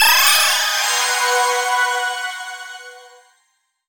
twinkle_glitter_dark_spell_01.wav